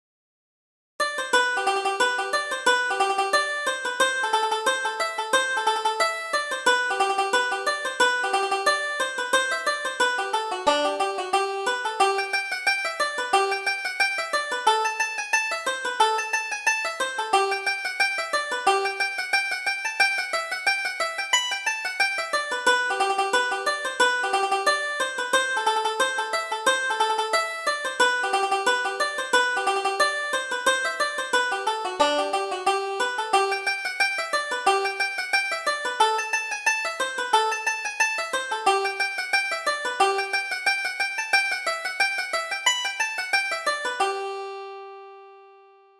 Reel: The Boys of Ballinchalla